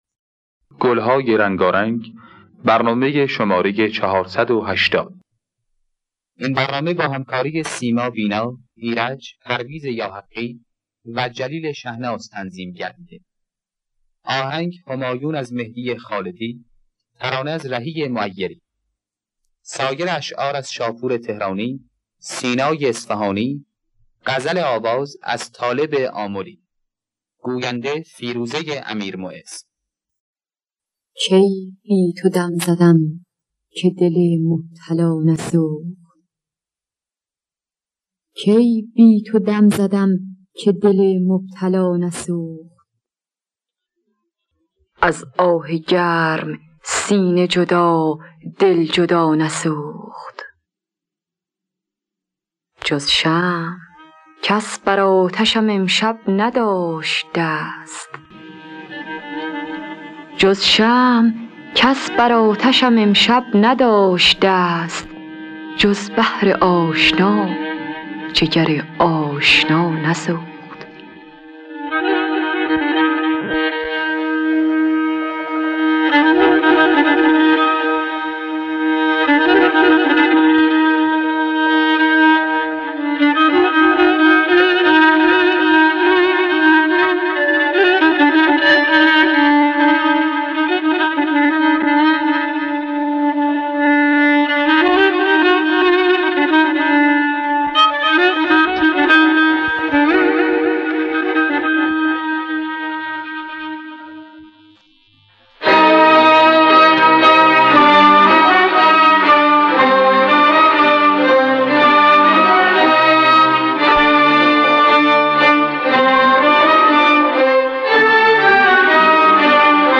دانلود گلهای رنگارنگ ۴۸۰ با صدای سیما بینا، ایرج در دستگاه همایون.